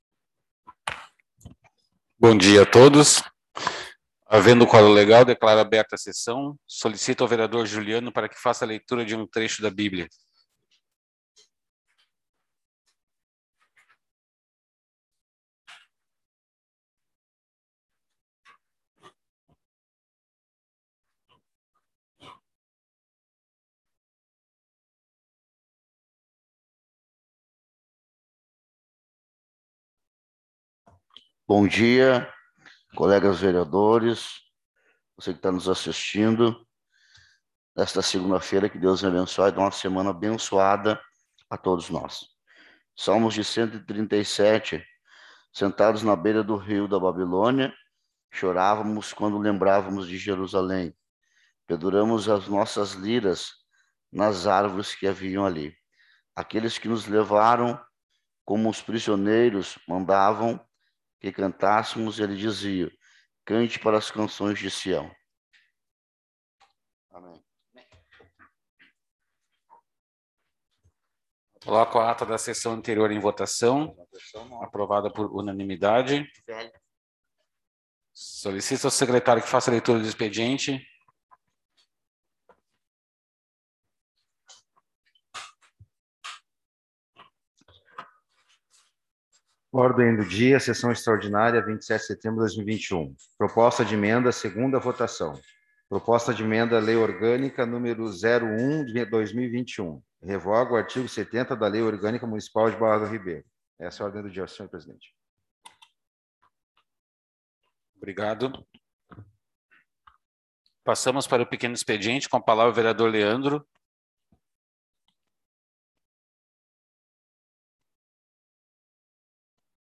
Sessão 27.09.2021